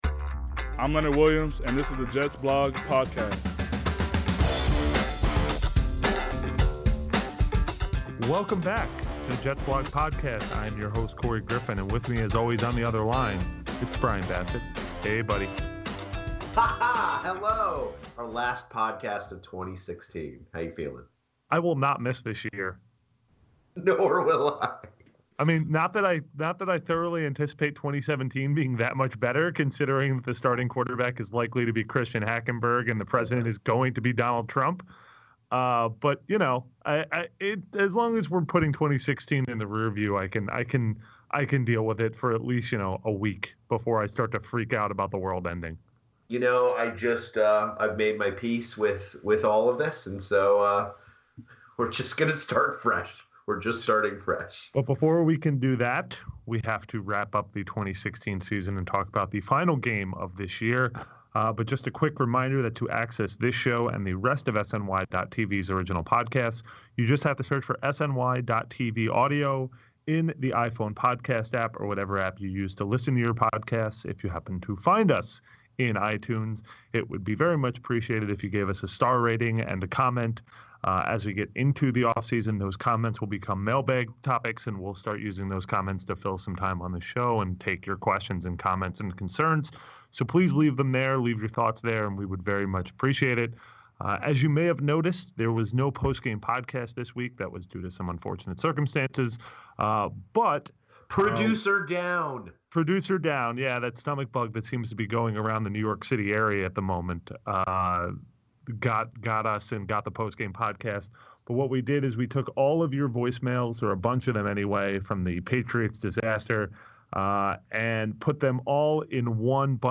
With help from your voicemails, they put a quick wrap on the Patriots debacle from last weekend. Then, they cover what’s left for Todd Bowles, Christian Hackenberg, and a fractured locker room as the Jets put the finishing touches on 2016 against the Buffalo Bills, who are leaving the Ryan Brothers behind.